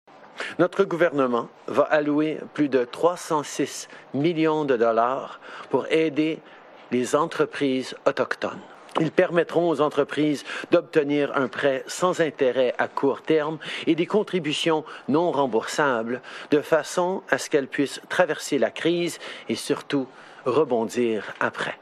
Lors de son point de presse quotidien sur la situation liée à la COVID-19 au Canada, le premier ministre Justin Trudeau a effet annoncé samedi qu’Ottawa et Washington avait décidé de prolonger l’interdiction des déplacements non essentiels entre les deux pays, sauf pour l’approvisionnement de biens.